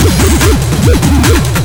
FXBEAT04-R.wav